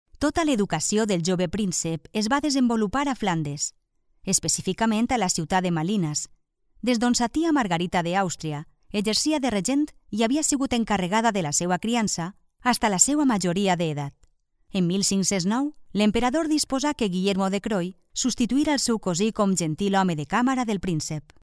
locutora valenciana, valencian voice over